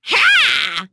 Nicky-Vox_Happy4.wav